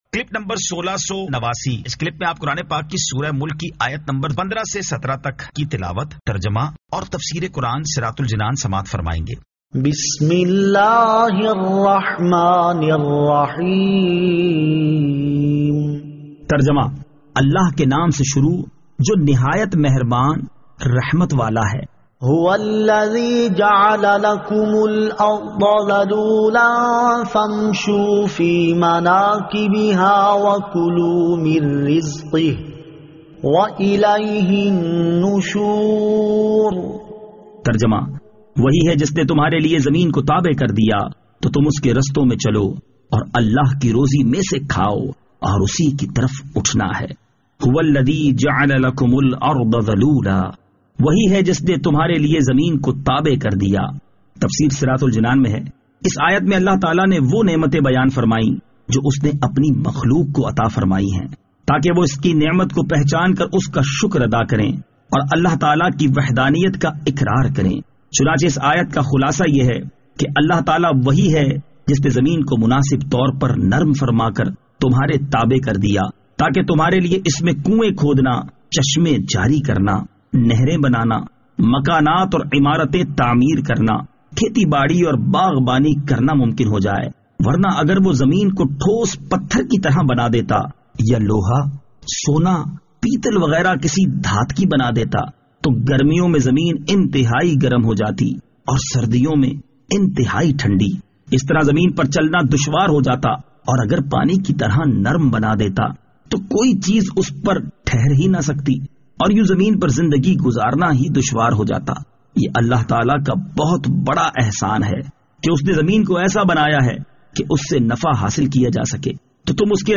Surah Al-Mulk 15 To 17 Tilawat , Tarjama , Tafseer